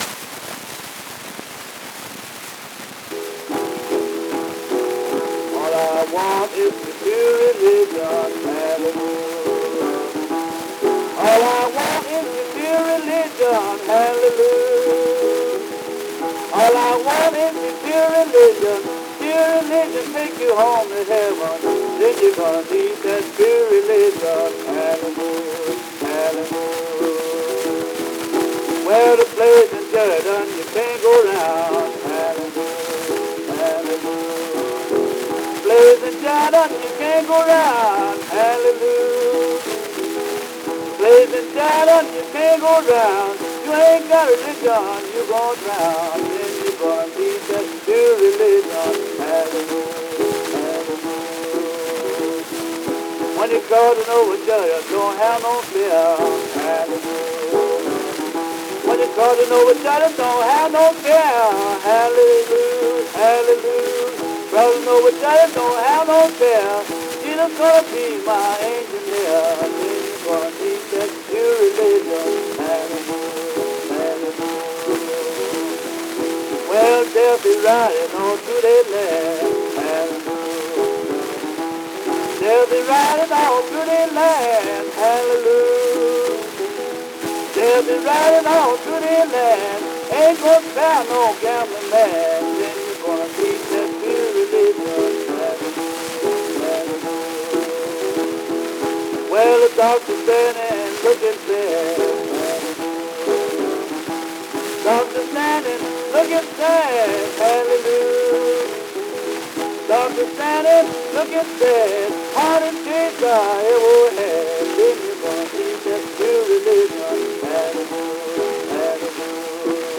Paramount 12386 was recorded at Rodeheaver Recording Laboratories in Chicago, Illinois, in late December of 1925 or early January of the following year, and was released around October 2, 1926.
In a performance somewhat evocative of his later and more famous “See That My Grave is Kept Clean”, Lemon’s rendition of the gospel song “All I Want is That Pure Religion” is haunting to say the least, showing us that the blues singer could preach fire and brimstone nearly as effectively as his contemporary Blind Willie Johnson.
All I Want is That Pure Religion, recorded c. 1925/1926 by Deacon L. J. Bates.